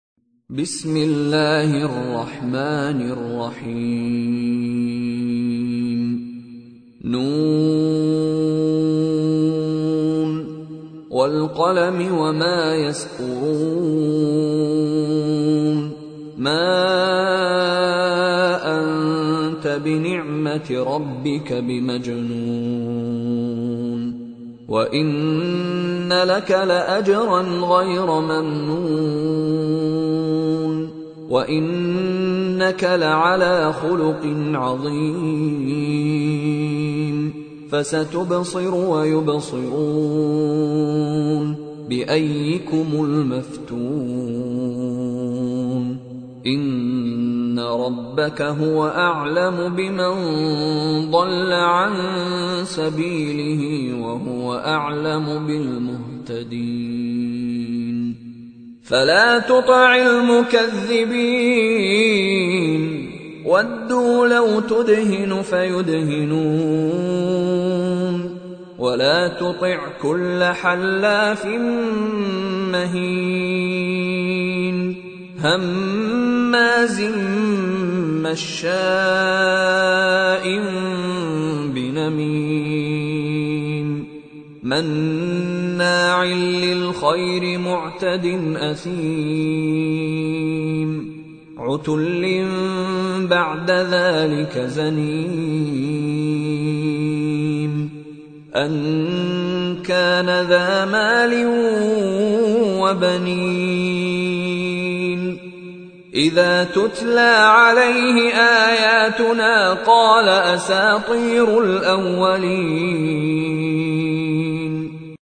پخش آنلاین و دانلود قرائت آیات هفته سوم شهریور ماه ، قرآن پایه ششم ، با قرائت زیبا و روحانی استاد مشاری رشید العفاسی